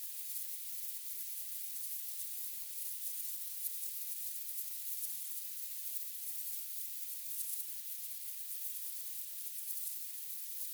Rebalanced mix of spray & beam loops
spray.wav